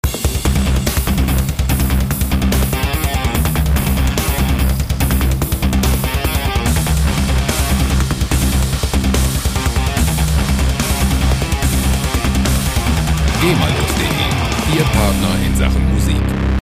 Heavy Metal Loops
Musikstil: Heavy Metal
Tempo: 145 bpm